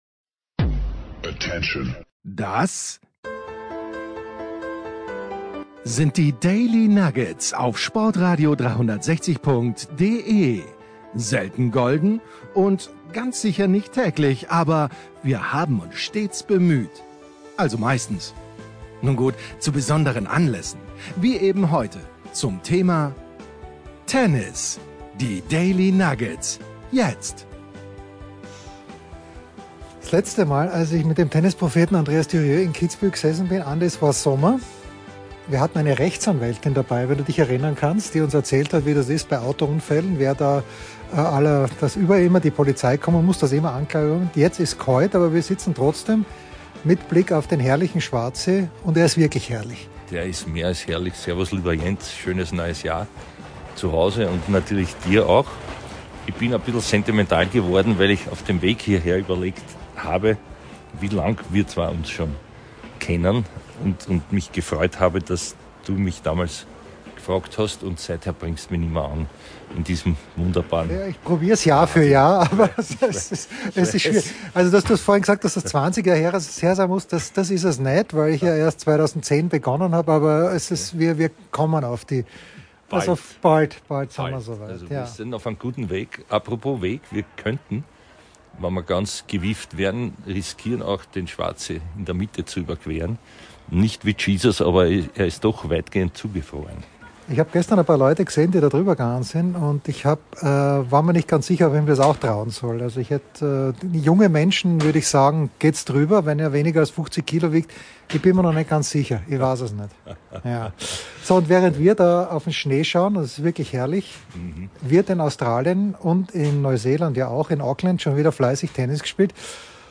Frierend am Schwarzsee in Kitzbühel über das ambivalente Verhältnis zu Nick Kyrgios oder Novak Djokovic plaudern